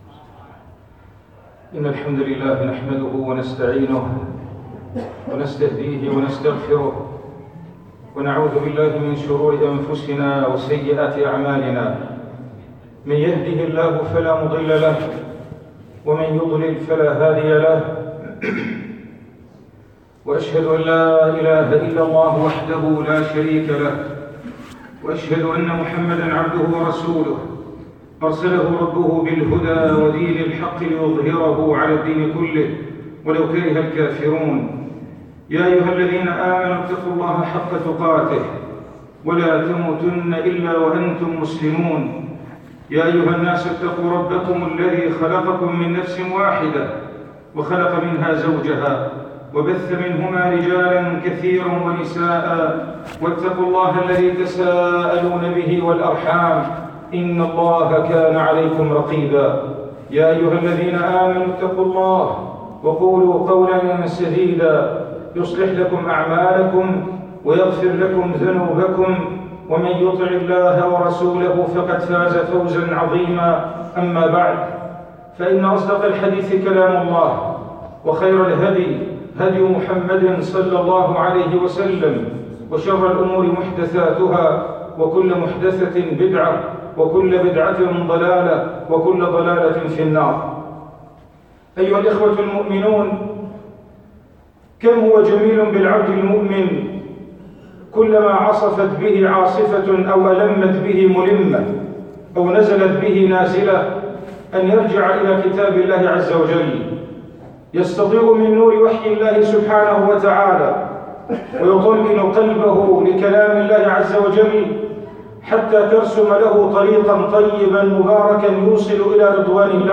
[منبر الجمعة]